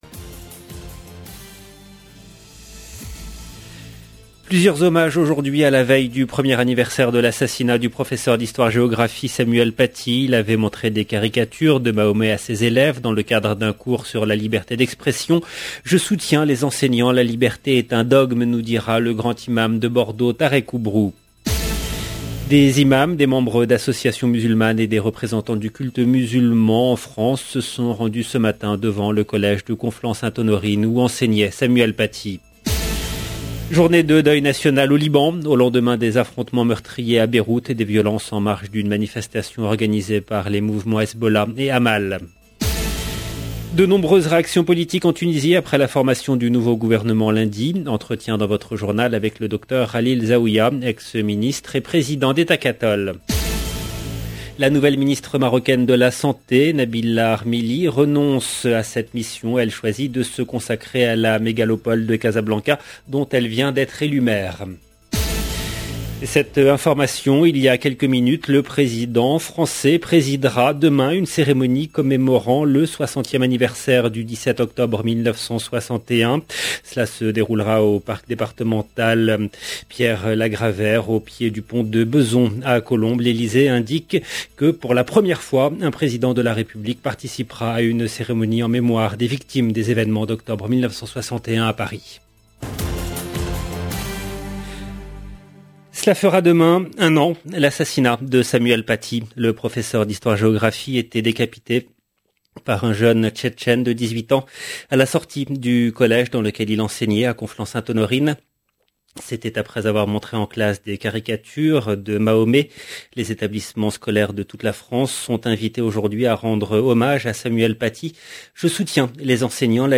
Le journal de 13h en langue française du 15/10/21
De nombreuses réactions politiques en Tunisie après la formation du nouveau gouvernement lundi. Entretien dans votre journal avec le docteur Khalil Zaouia, ex ministre et président d’Ettakatol.